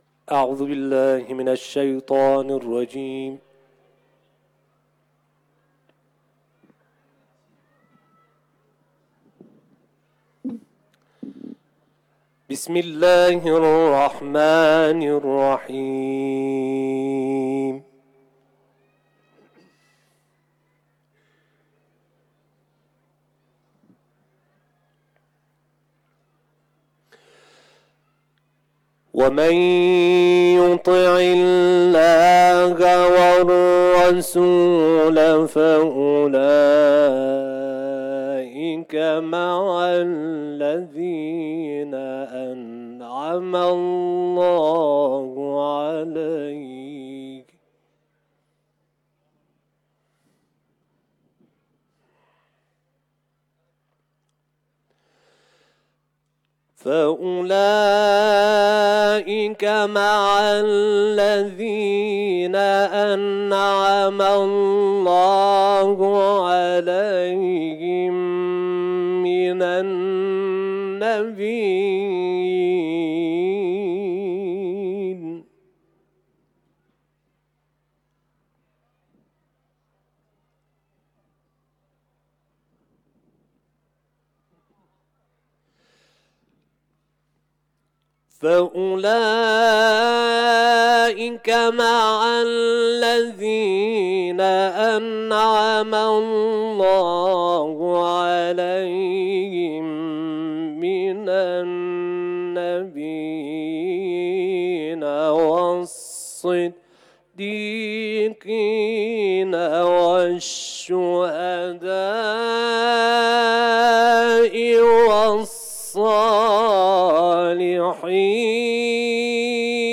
آیات 69 تا 74 سوره «نساء» را در حرم مطهر رضوی، تلاوت کرده است.
تلاوت